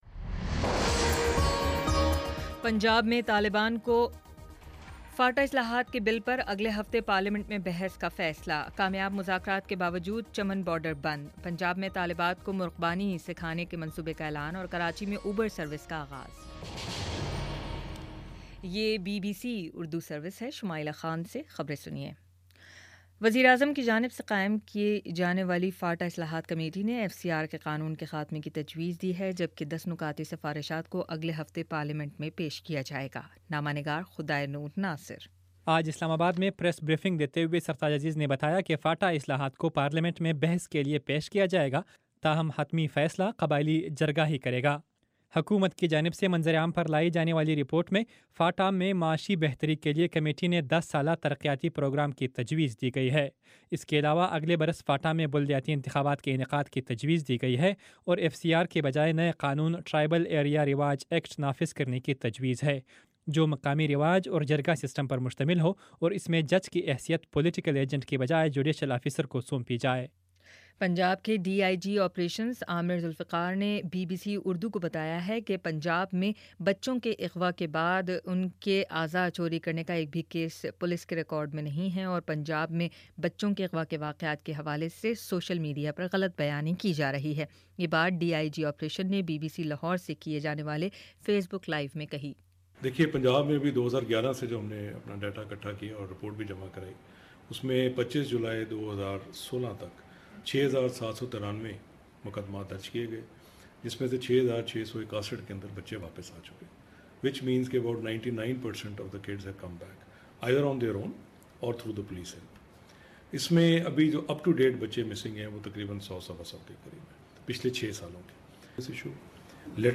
اگست 25 : شام سات بجے کا نیوز بُلیٹن